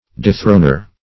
\de*thron"er\